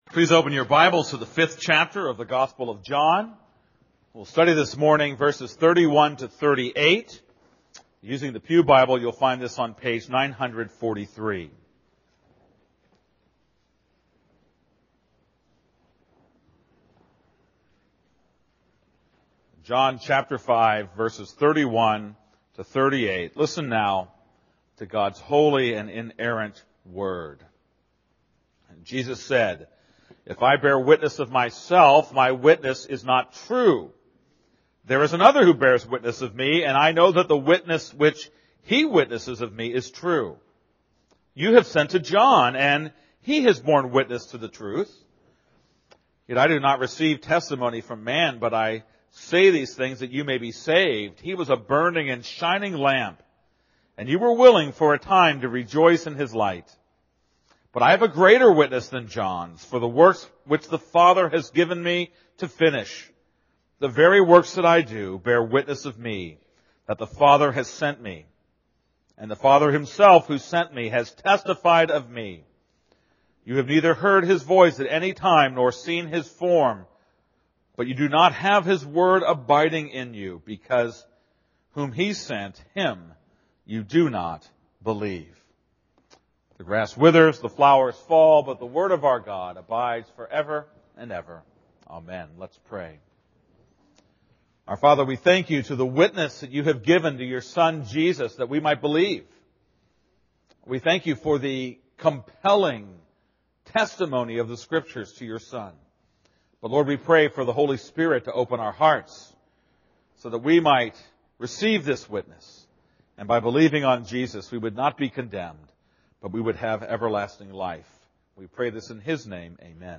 This is a sermon on John 5:31-38.